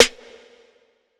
TC2 Snare 31.wav